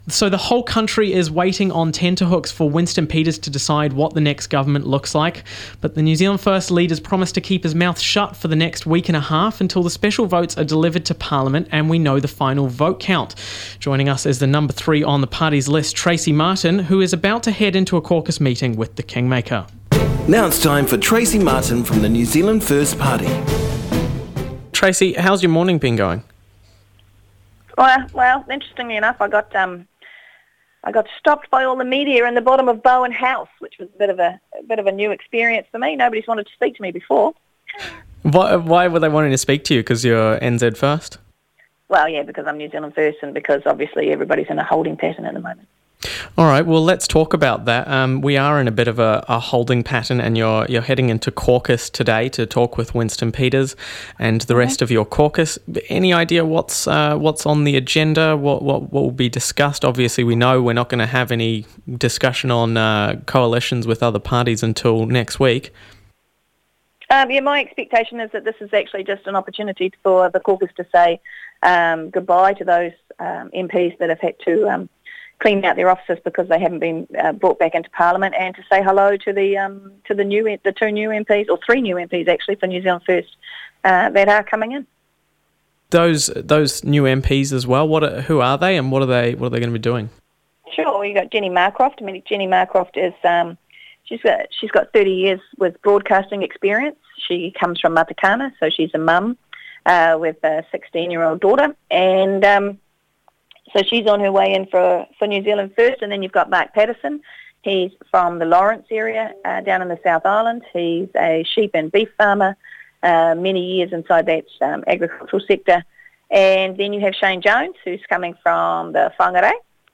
Joining us is the Number three on the party’s list, Tracey Martin who is about to head into a caucus meeting with the kingmaker.